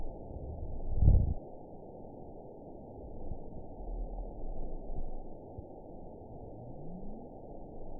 event 919930 date 01/28/24 time 21:54:28 GMT (1 year, 3 months ago) score 9.53 location TSS-AB03 detected by nrw target species NRW annotations +NRW Spectrogram: Frequency (kHz) vs. Time (s) audio not available .wav